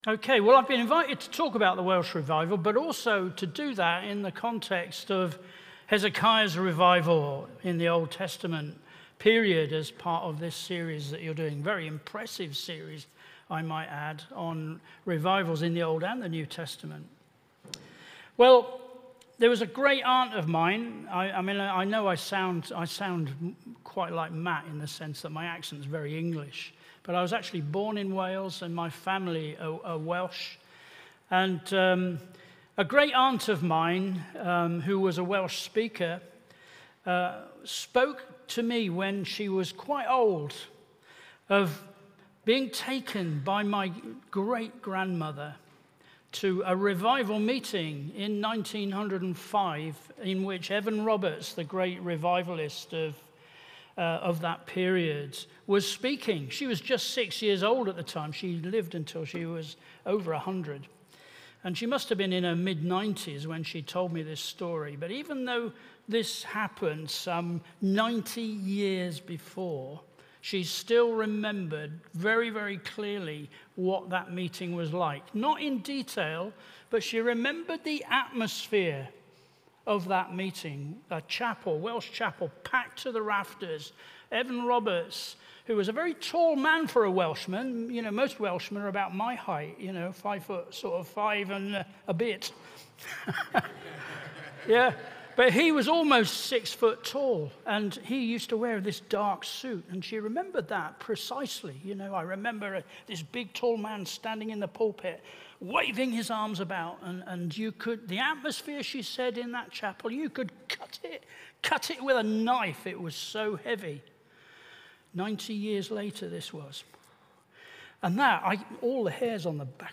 JULY-6-Full-Sermon.mp3